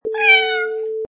Sound production: Cat Meow 2
A cat meows
Product Info: 48k 24bit Stereo
Category: Animals / Cats
Try preview above (pink tone added for copyright).
Cat_Meow_2.mp3